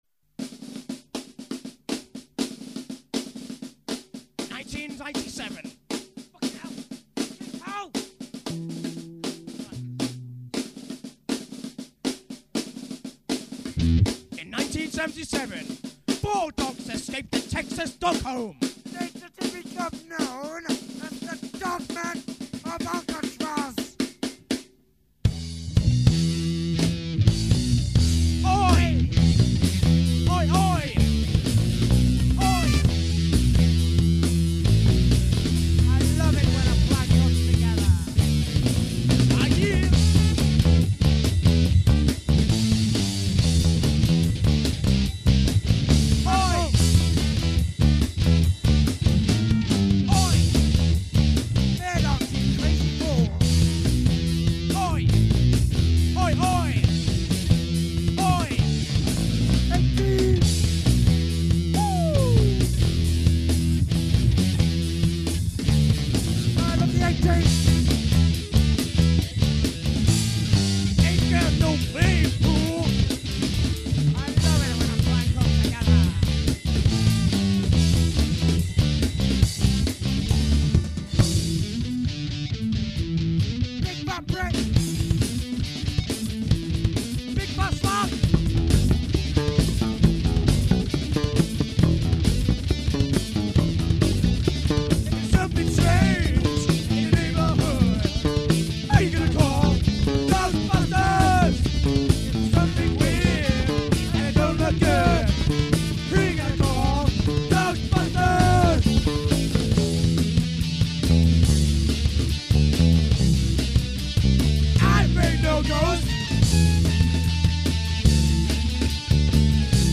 ----East coast/West coast Hardcore, Gregorian Chant----